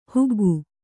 ♪ huggu